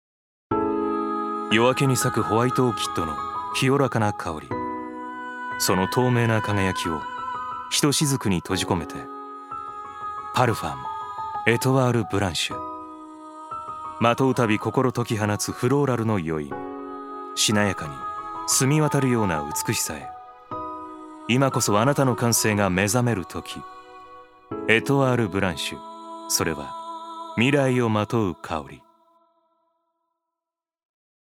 所属：男性タレント
ナレーション５